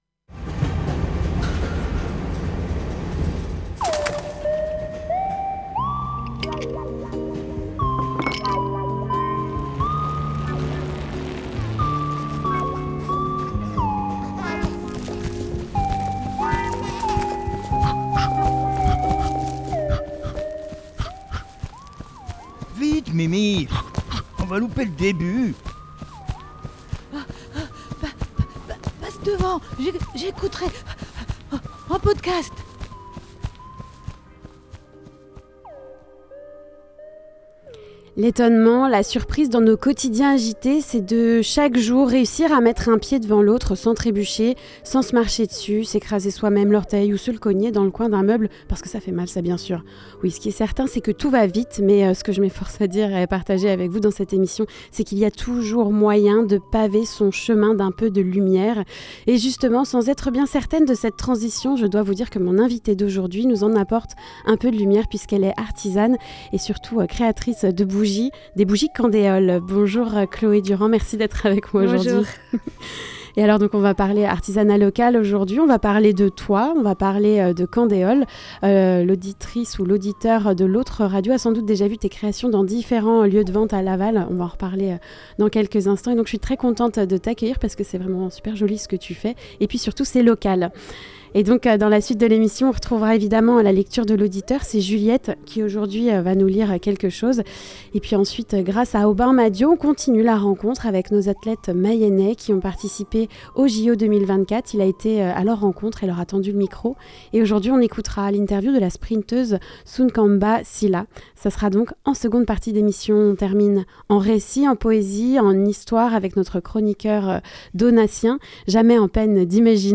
L'invitée avec moi en studio